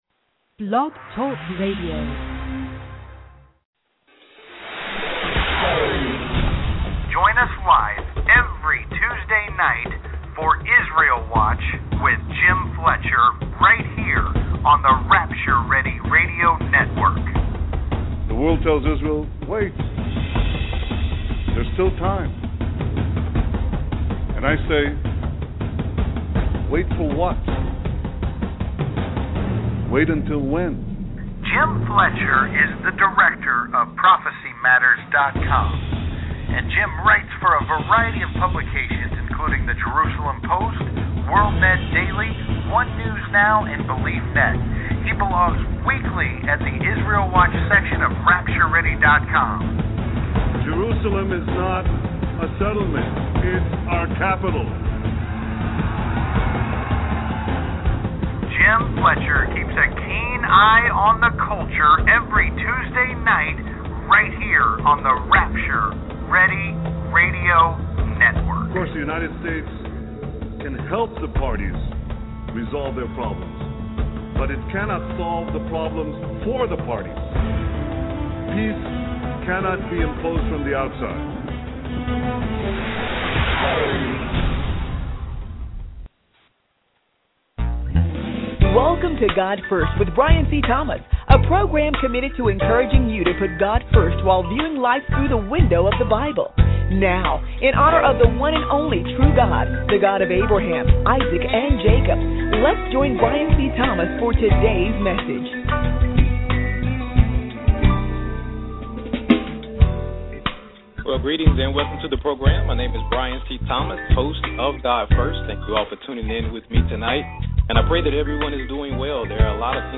There is a 2 minute intro before the interview.)